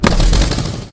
tank-engine-load-4.ogg